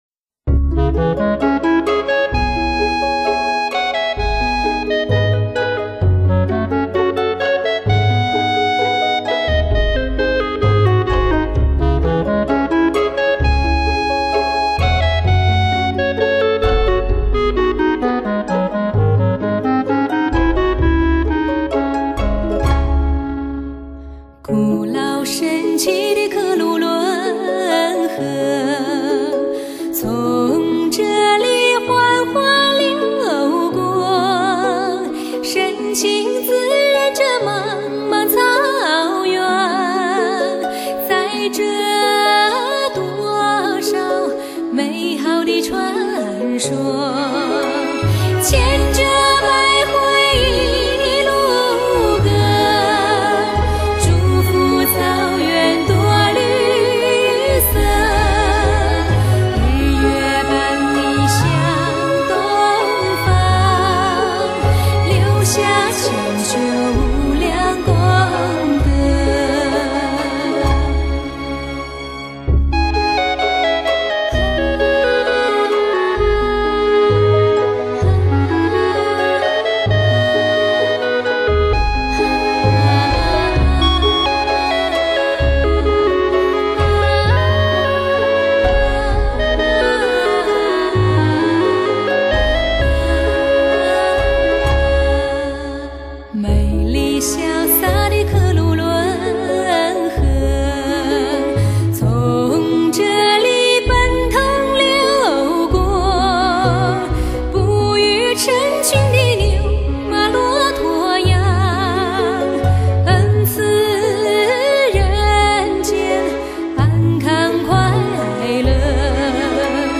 Hi-Fi多音色高临场感6.1SURROUND 360度环绕特效天碟。